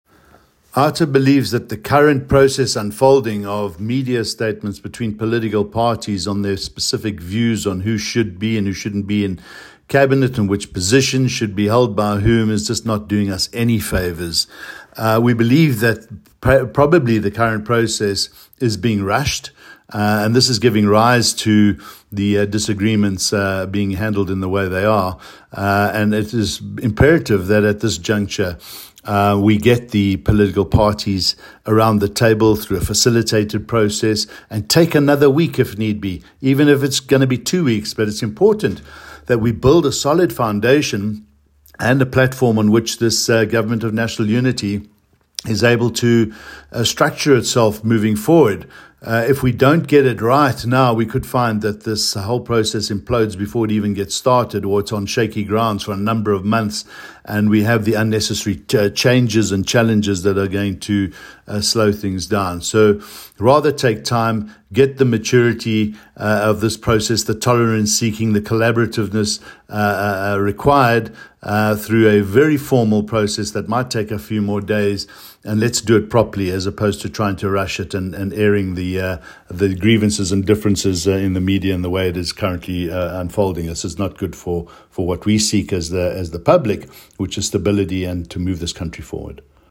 A soundclip with comment